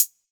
Hats & Cymbals
Hat_Closed_09.wav